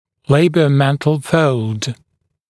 [ˌleɪbɪə(u)’mentl fəuld][ˌлэйбио(у)’мэнтл фоулд]губоподбородочная складка